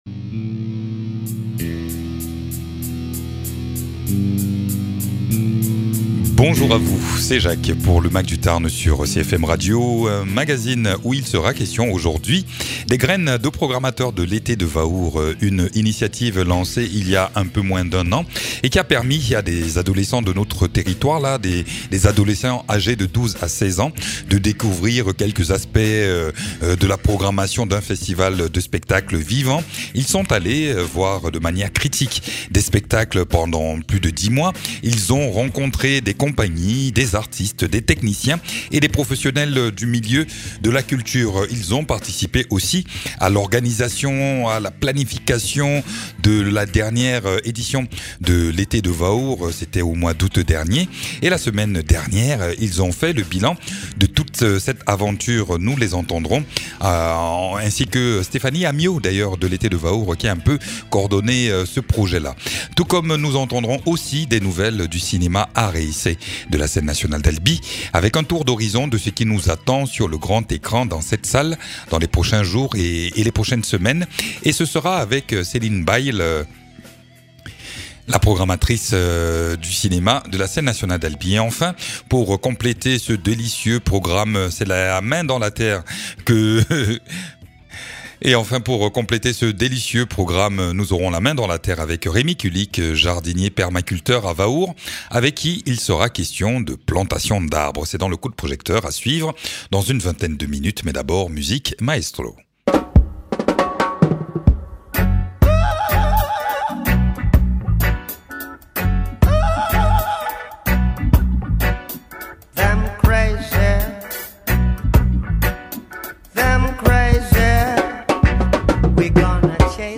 Epilogue du projet graines de programmateurs de l’été de vaour avec une dizaine d’adolescents qui sont accompagnés depuis février dernier à découvrir les coulisses du spectacle vivant. Ils nous livrent aujourd’hui leur ressenti. Et puis, notre rendez-vous mensuel avec le cinéma art et essai de la Scène Nationale d’Albi.